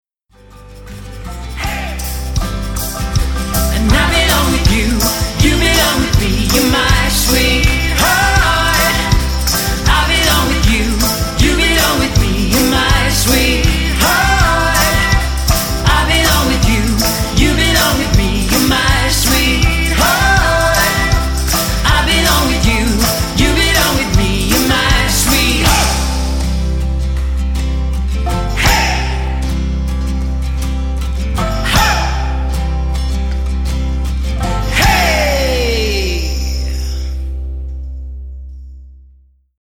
high-energy vintage wedding band
• Both male and female vocalists